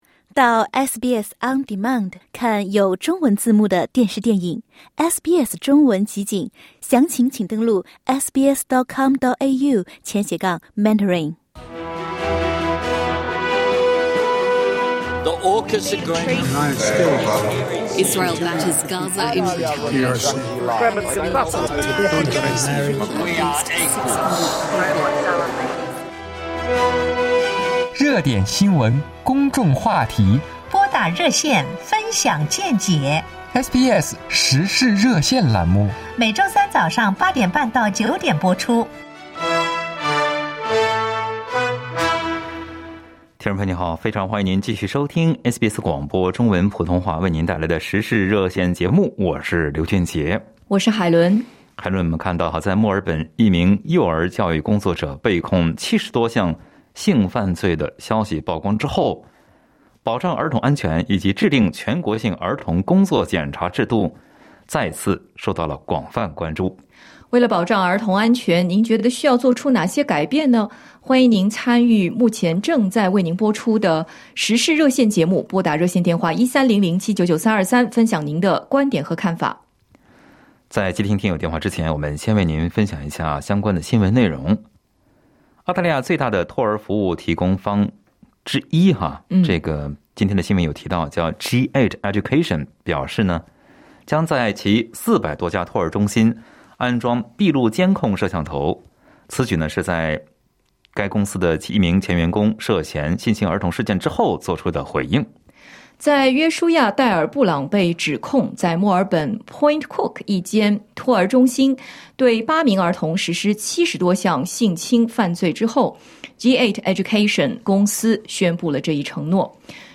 SBS中文普通话《时事热线》节目听友也就保障儿童安全的方法分享了见解。